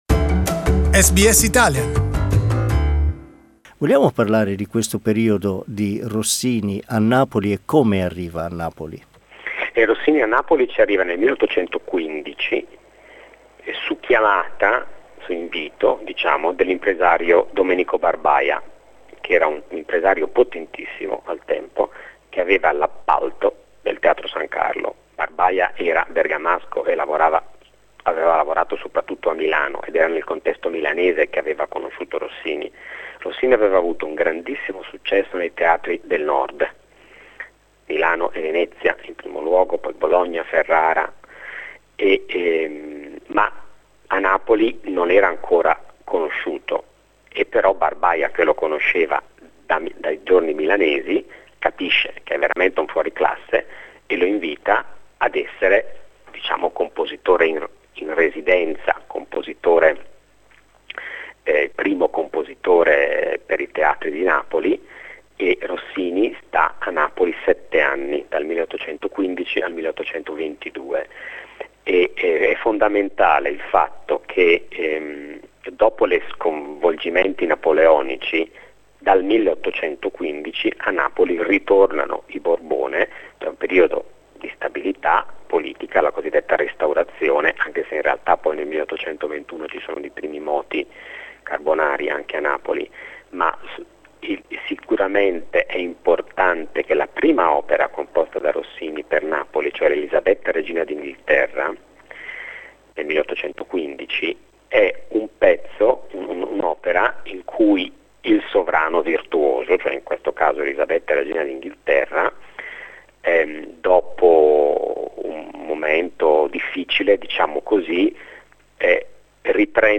In this series we will hear from some of the leading experts on Rossini's life and music, as well as listen to some pieces representative of his rich musical repertoire.